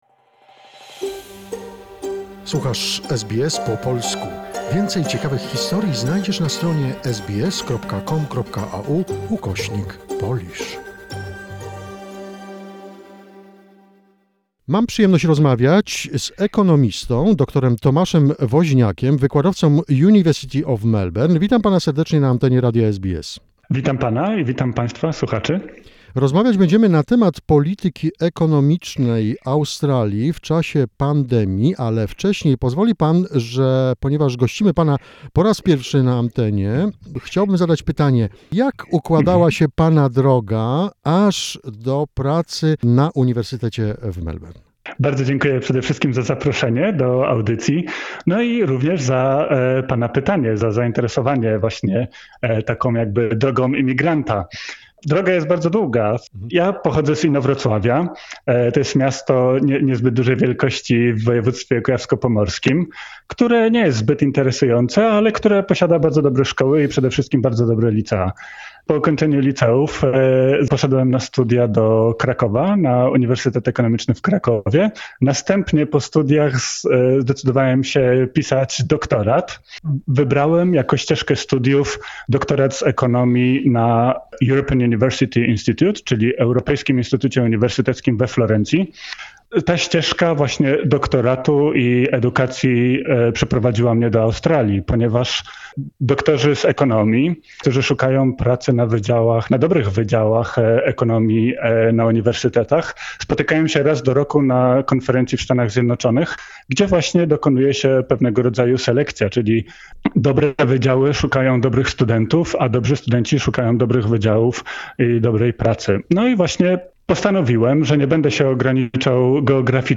This is the first part of the conversation about the challenges facing the Australian economy.